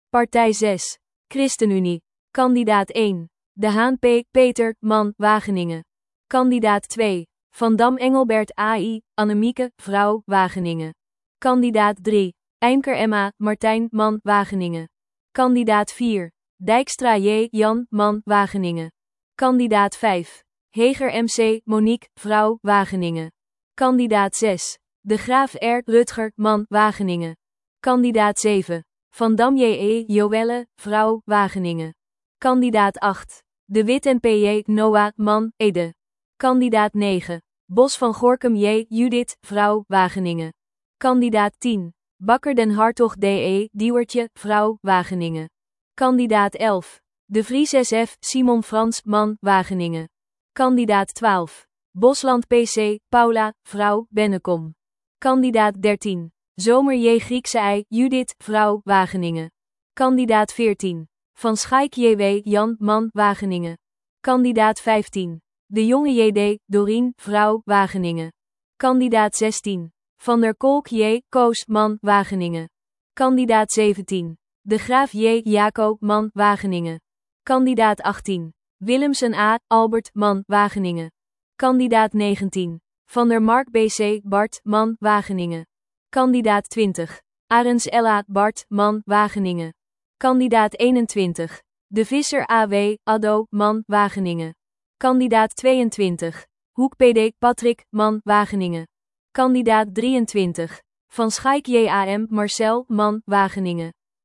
(Externe link) Gesproken kandidatenlijsten in mp3 1.